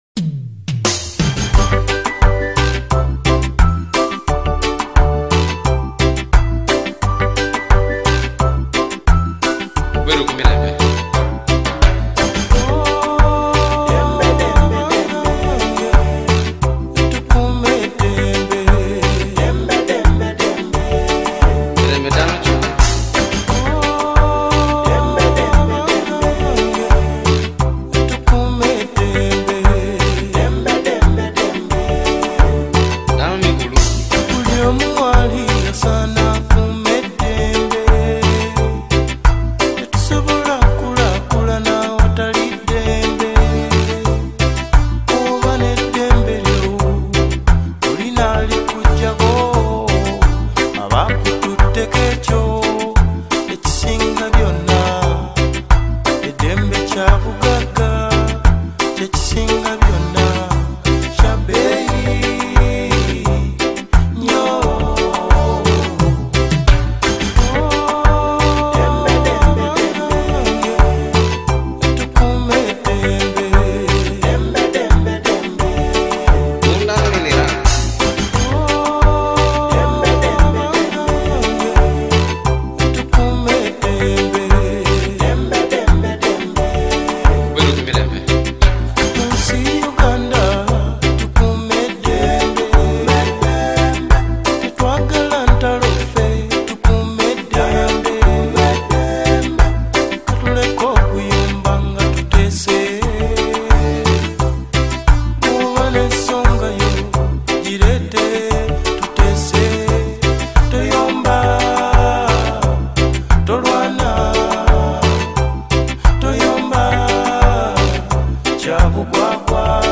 Genre: Ragga